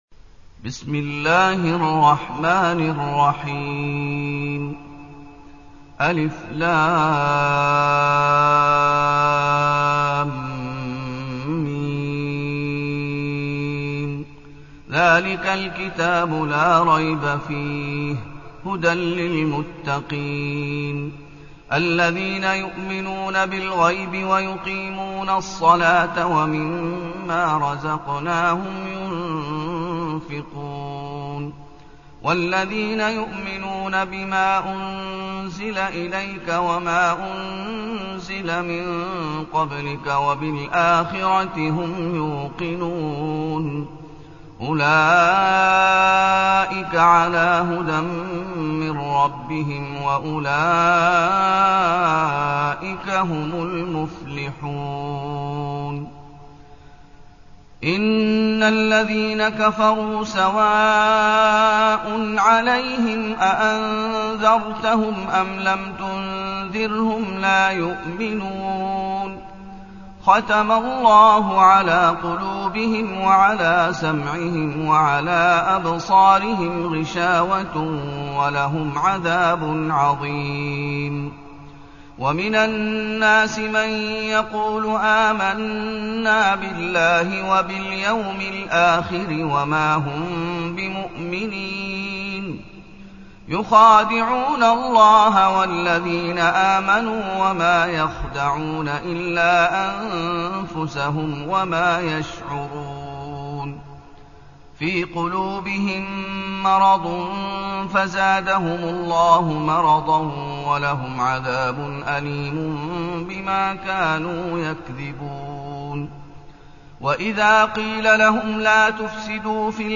المكان: المسجد النبوي الشيخ: فضيلة الشيخ محمد أيوب فضيلة الشيخ محمد أيوب البقرة